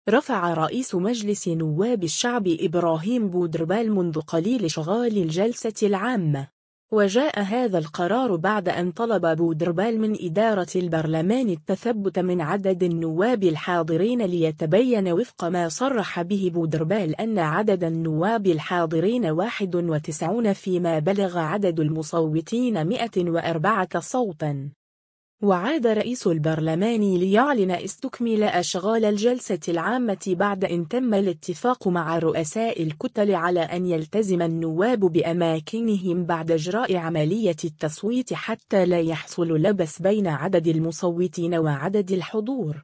رفع رئيس مجلس نواب الشعب إبراهيم بودربالة منذ قليل اشغال الجلسة العامة.